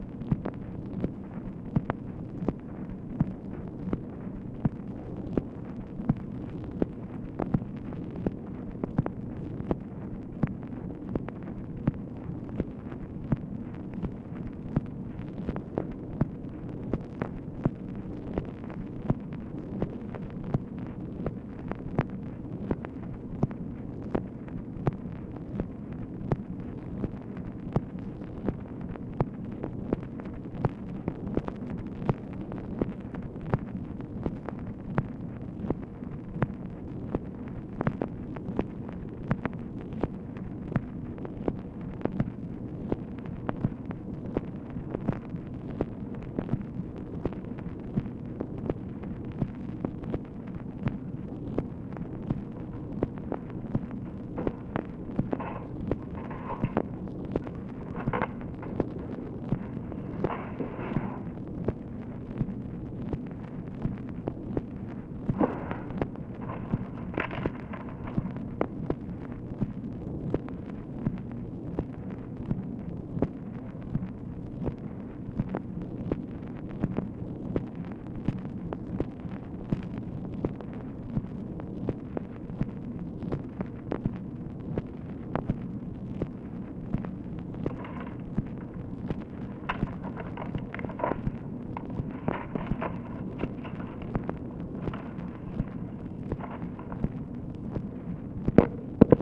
OFFICE NOISE, February 25, 1964
Secret White House Tapes | Lyndon B. Johnson Presidency